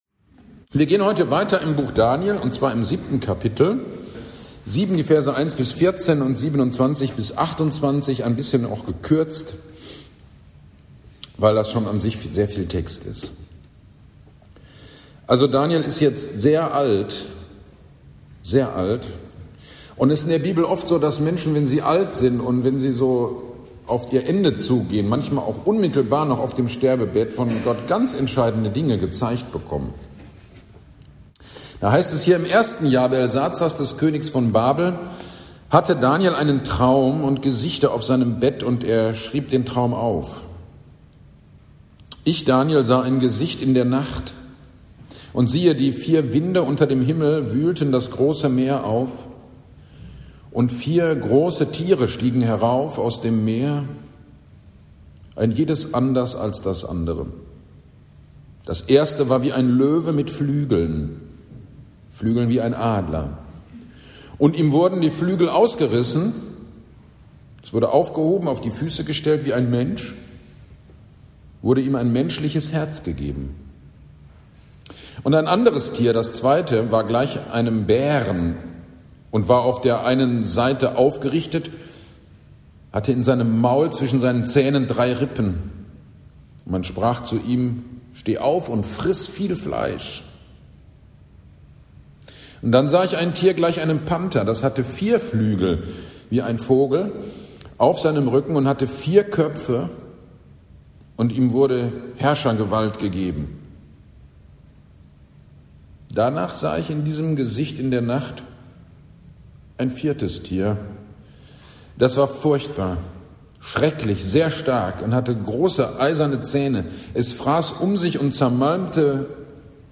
GD am 27.10.24 Predigt zu Daniel 7 - Kirchgemeinde Pölzig